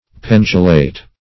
Pendulate \Pen"du*late\
pendulate.mp3